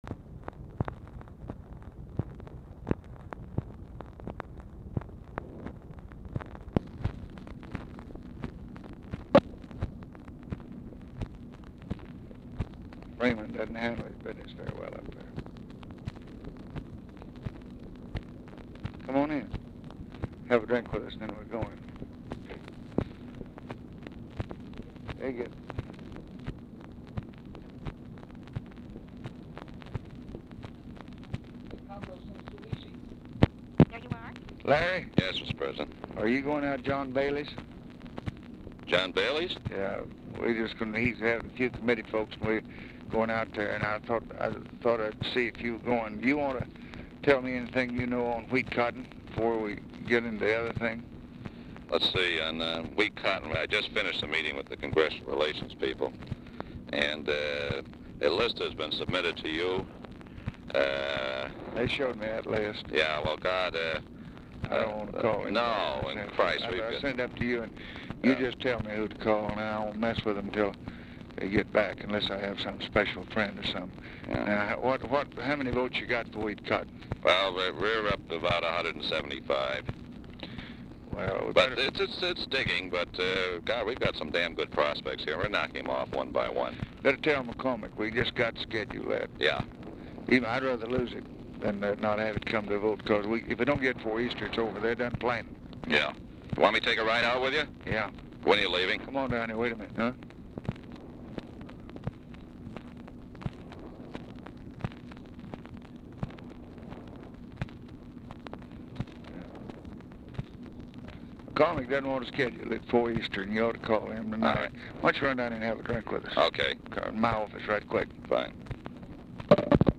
Telephone conversation # 2551, sound recording, LBJ and LARRY O'BRIEN, 3/18/1964, 6:50PM
OFFICE CONVERSATION PRECEDES CALL
Format Dictation belt